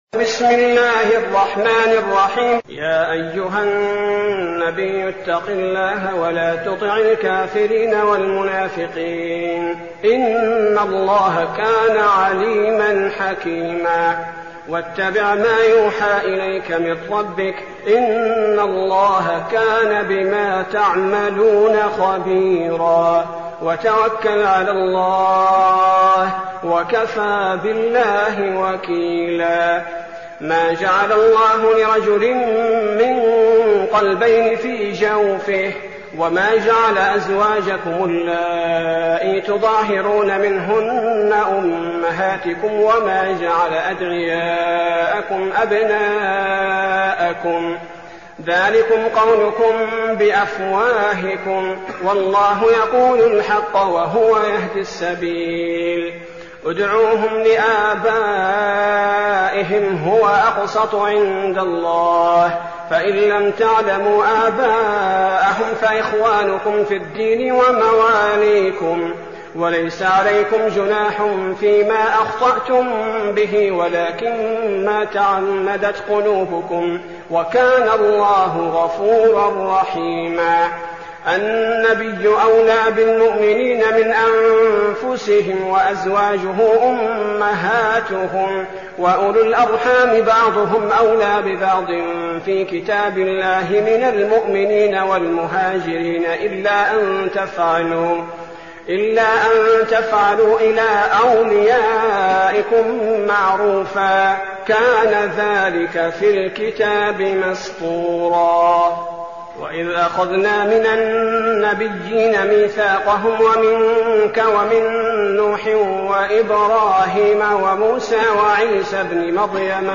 المكان: المسجد النبوي الشيخ: فضيلة الشيخ عبدالباري الثبيتي فضيلة الشيخ عبدالباري الثبيتي الأحزاب